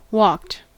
Ääntäminen
Ääntäminen US : IPA : [wɔːkt] Haettu sana löytyi näillä lähdekielillä: englanti Käännöksiä ei löytynyt valitulle kohdekielelle.